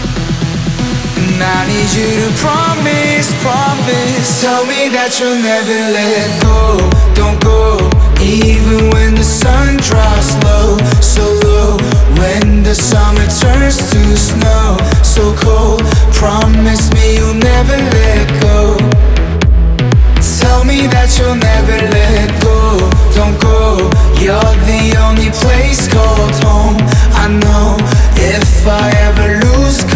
2024-10-04 Жанр: Танцевальные Длительность